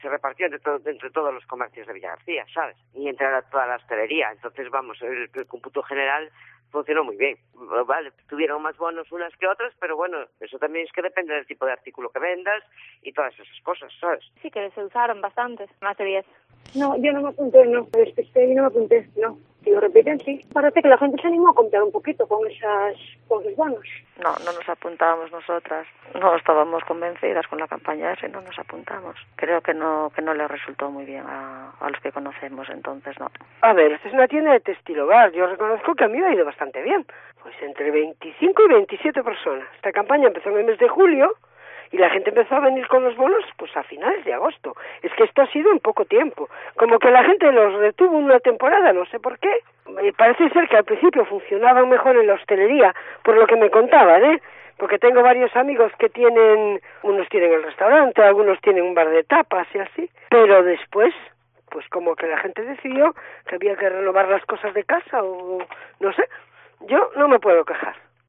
Opiniones de comerciantes de Vilagarcía sobre la campaña del Bono Son da Casa